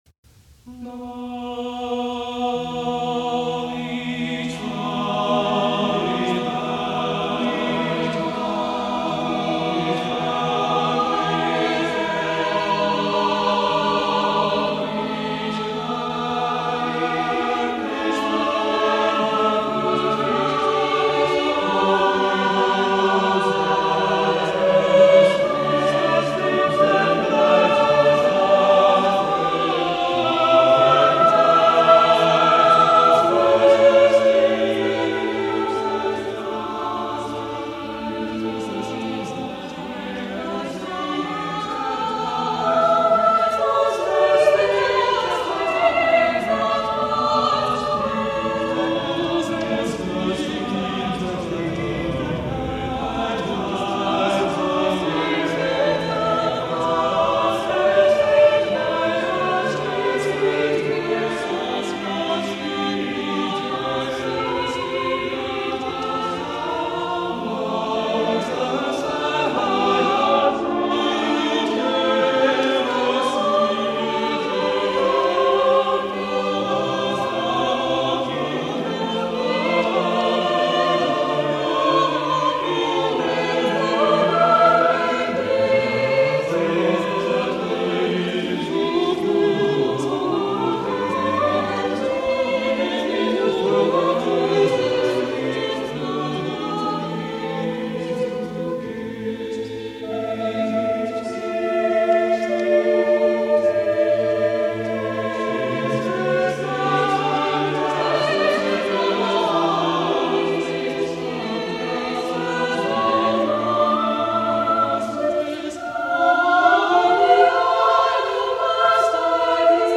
Known perhaps more in his day as an organist, Gibbons, in his ‘The First Set of Madrigals and Mottets’ of 1612, demonstrates an intimate and skillful command of madrigal composition.